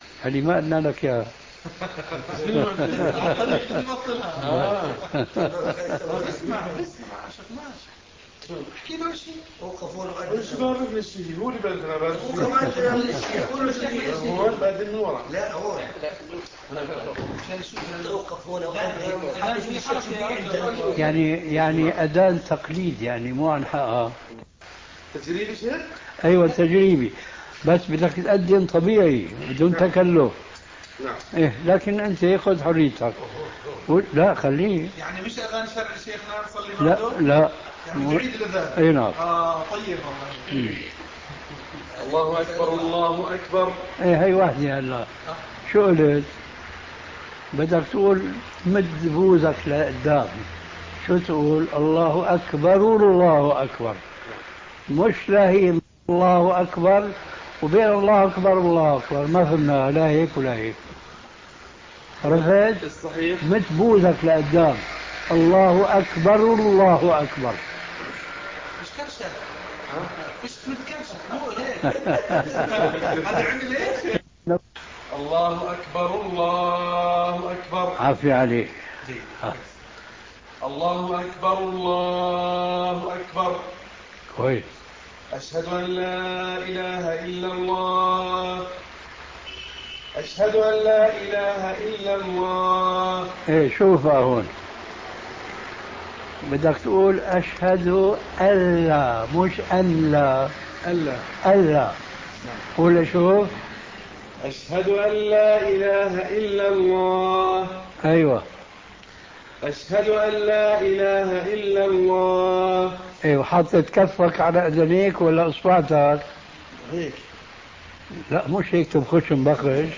In the audio below, Shaikh al-Albaani is teaching some brothers how to correctly perform the adhaan, at about 10:38 in to it, he recommends Mahmood Khaleel al-Husari’s recitaiton:
shaikh-al-albaanis-adhaan.mp3